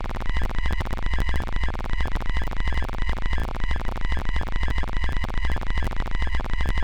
sci-fi machine 5 (ambient)
ambient device electronic machine science-fiction sci-fi sound effect free sound royalty free Sound Effects